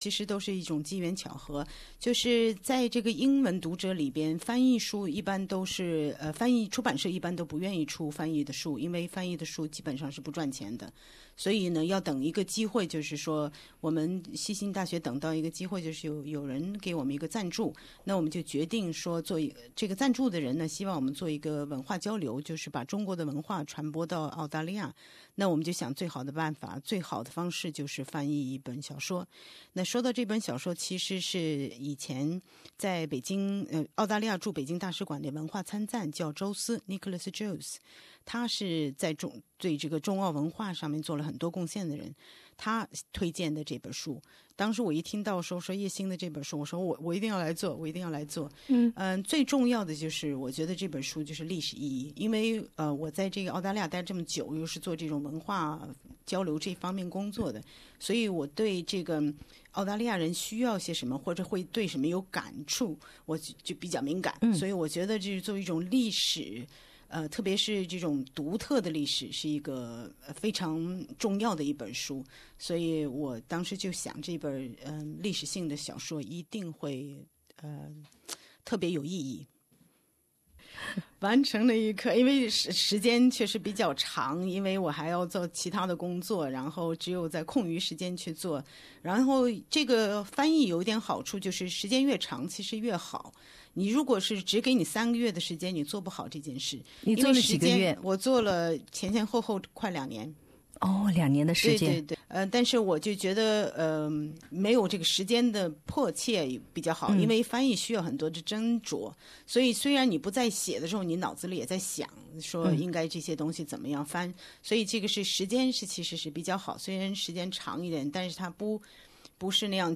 采访